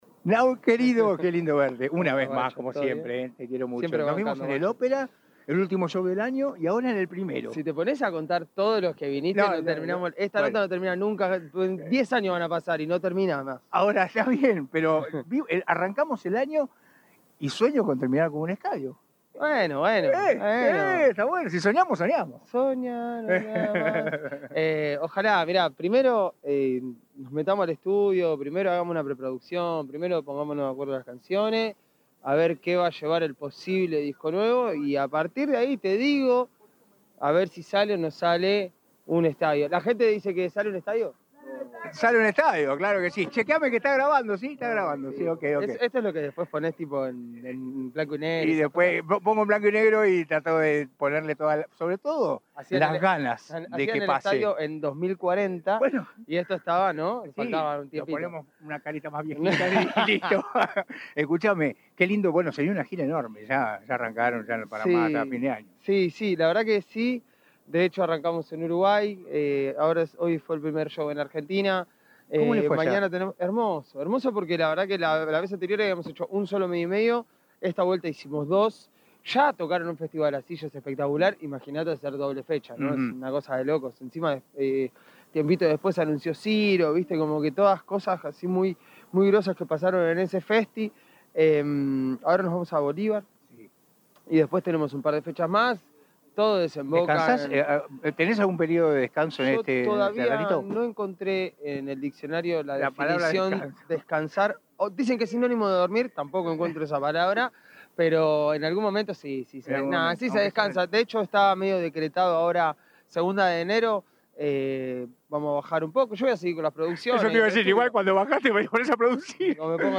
Entrevistas
Cruzando el Charco se presentò en un show gratuito en el Summer Car Show, el evento de verano que combina música en vivo y actividades al aire libre sobre la Ruta 11.